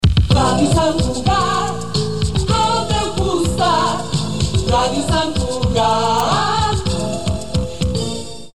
Indicatiu cantat